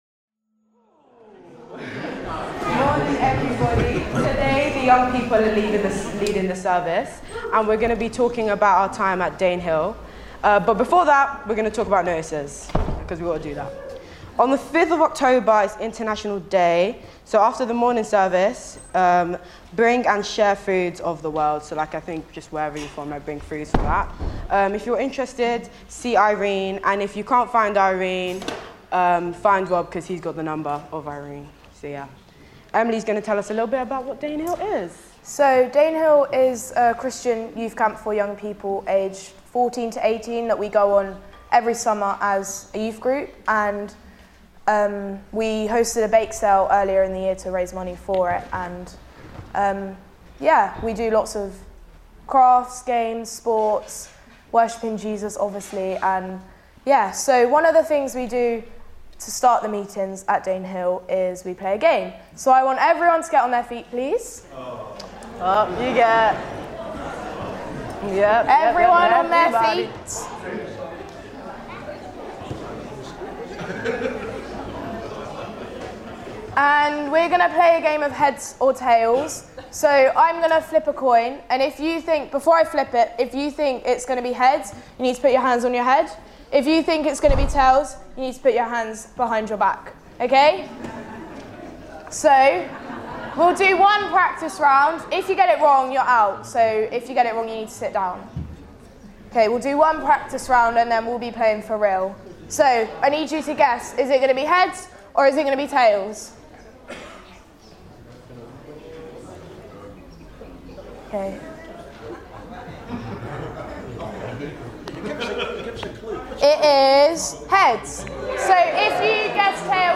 14 September 2025 – Morning Service
Service Type: Morning Service